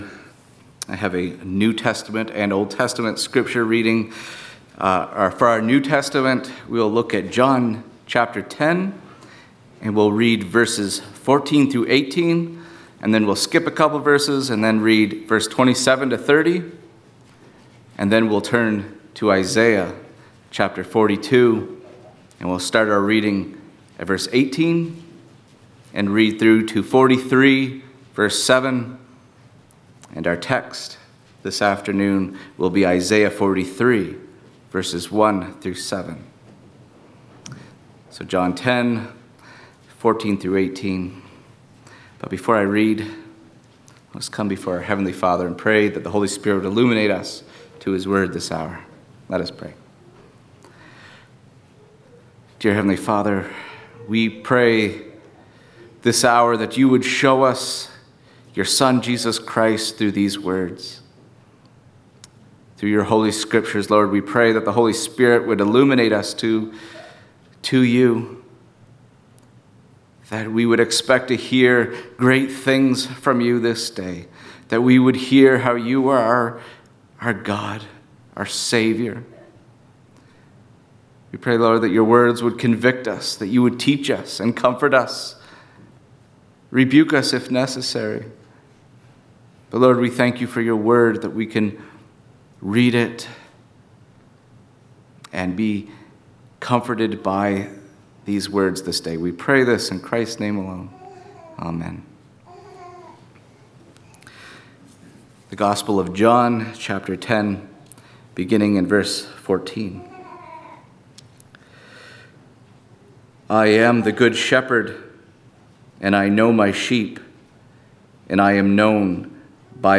4.-sermon-_-Yahweh-is-the-Lord-your-God.mp3